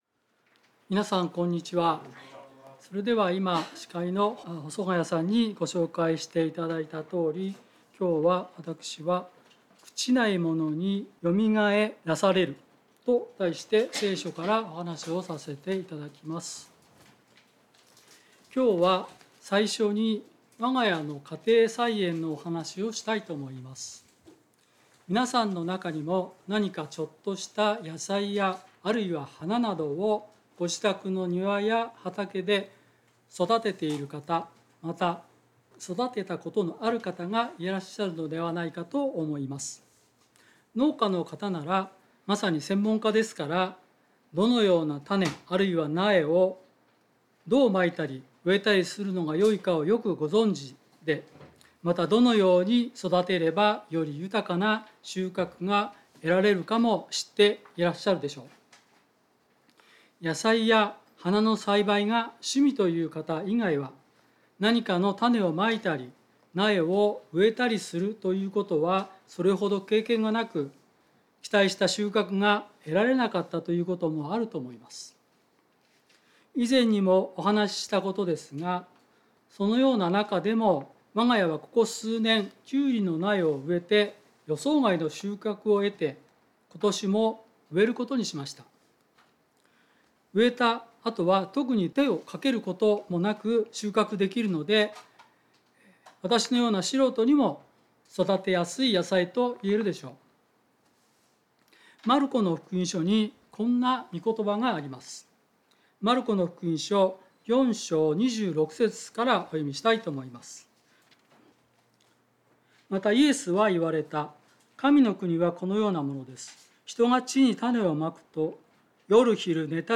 聖書メッセージ No.275